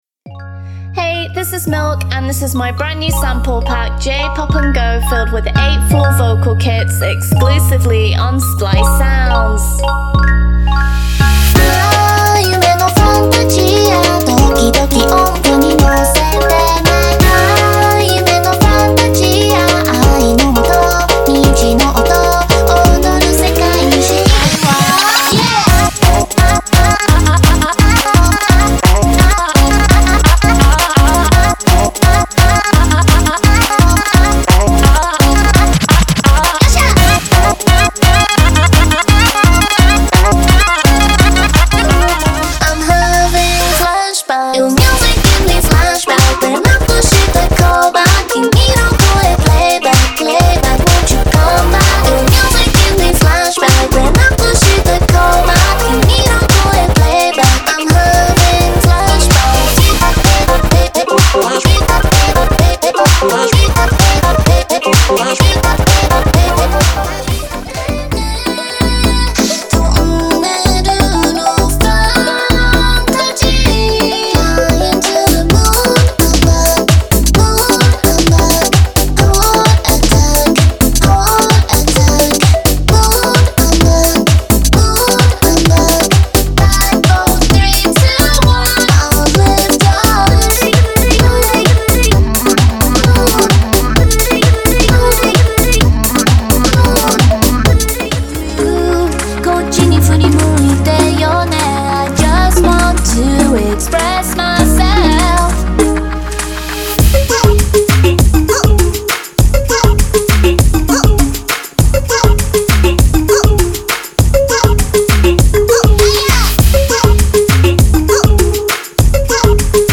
-8个完整的声乐套件
-包括干和湿的干
-21个额外的声乐